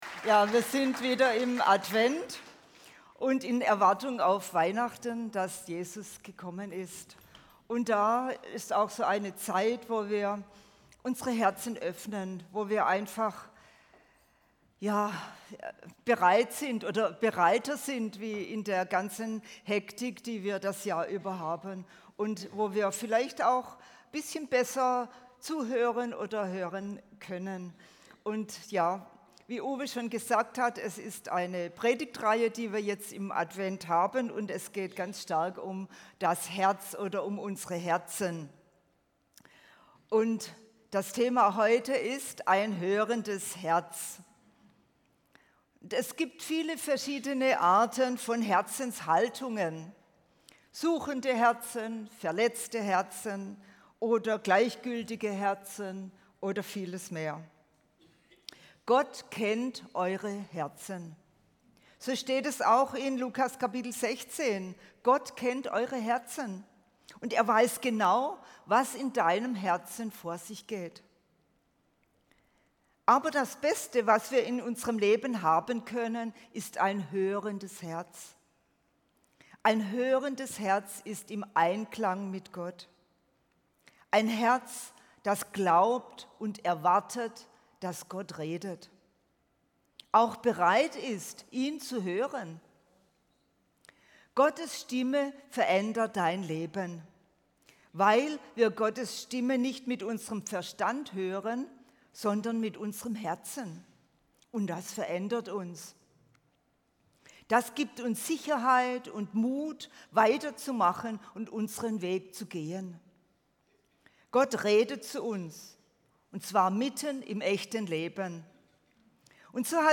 Ein hörendes Herz erkennt Gottes Stimme mitten im Alltag – und genau darum geht es in dieser Predigt. Entdecke, wie Gott heute zu dir spricht und warum echtes Hören dein Leben verändern kann.